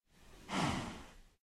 cowhuff.mp3